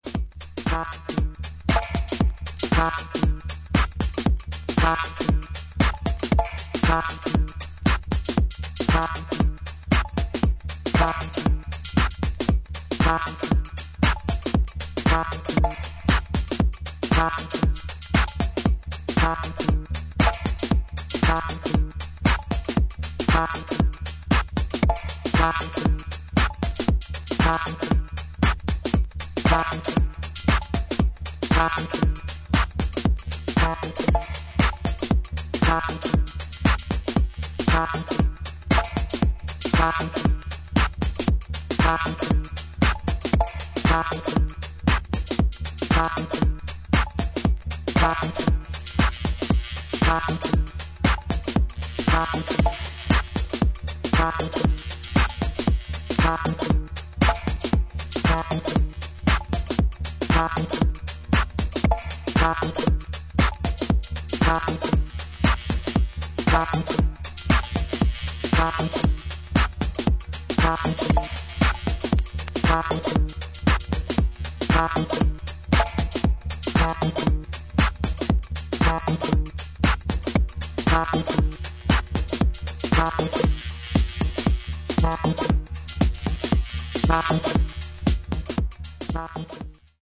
ハードウェアサウンドの面白さを堪能できる一枚です。